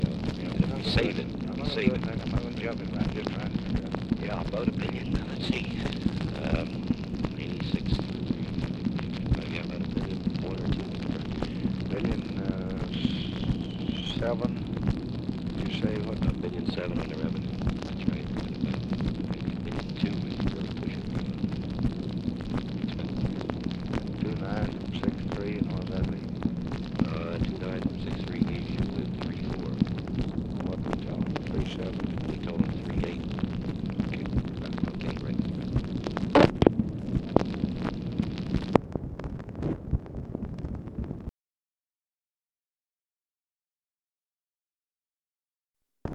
ALMOST INAUDIBLE DISCUSSION OF FEDERAL BUDGET LEVELS
Conversation with CHARLES SCHULTZE, June 28, 1965
Secret White House Tapes